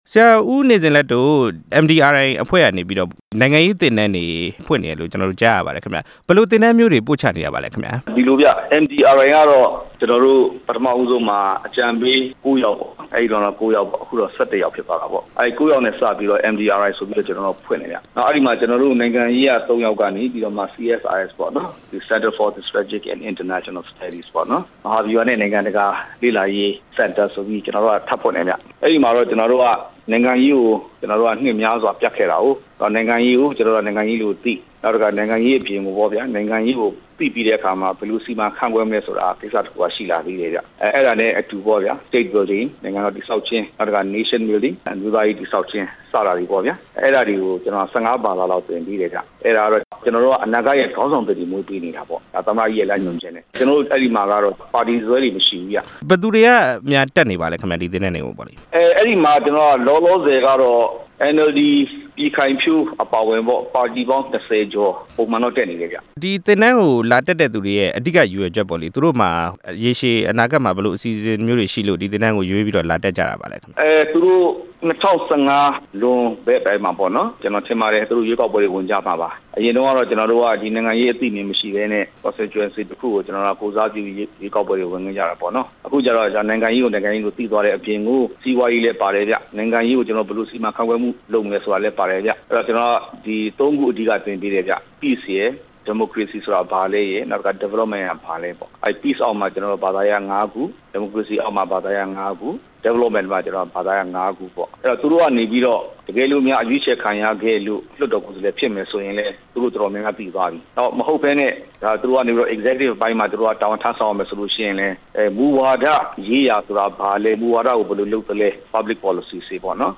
သမ္မတအကြံပေးတွေဖွင့်တဲ့ နိုင်ငံရေးသင်တန်းအကြောင်း ဆက်သွယ်မေးမြန်းချက်